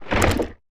creaking_attack4.ogg